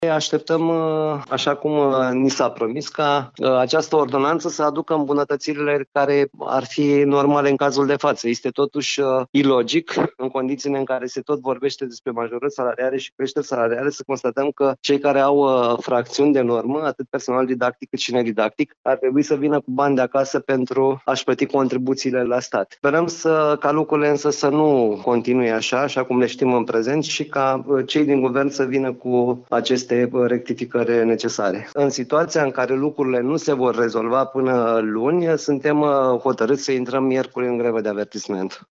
Acesta  a afirmat, la Radio Iaşi, că actualul mod de calcul al obligaţiilor fiscale pentru cei care nu lucrează cu normă întreagă este total dezavantajos.